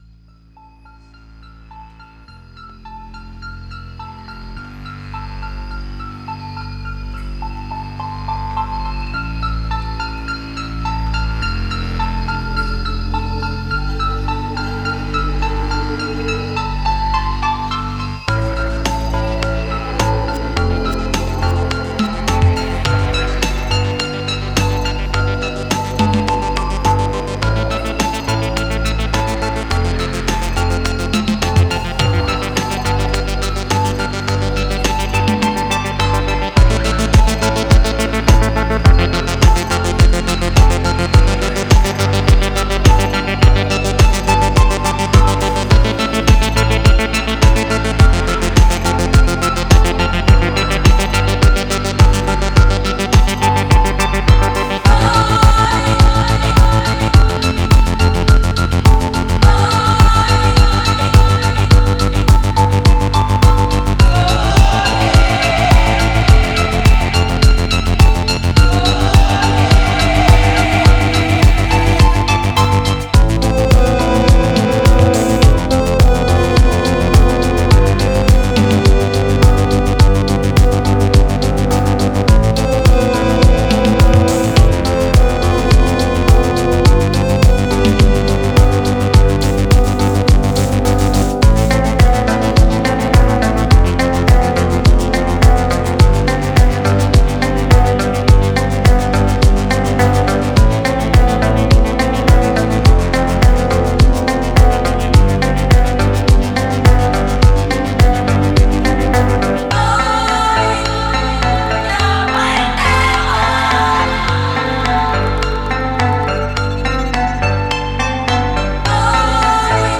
Mixed